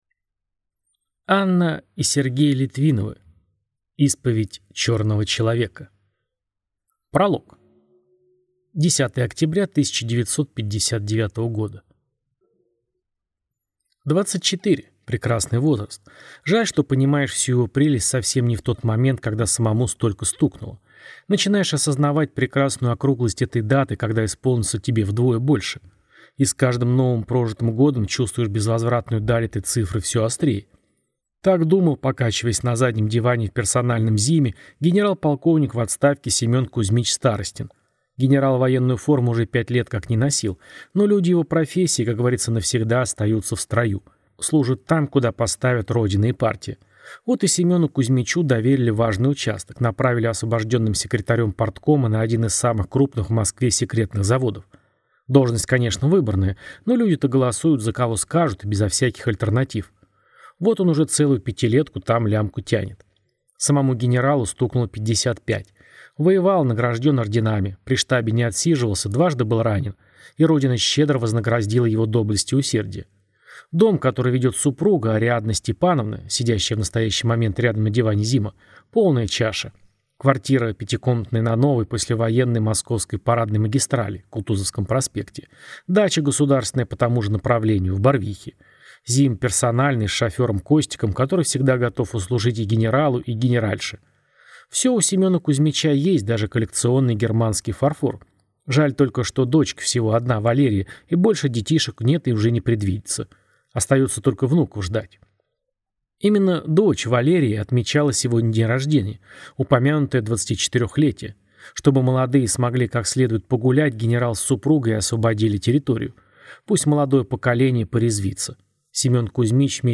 Аудиокнига Исповедь черного человека | Библиотека аудиокниг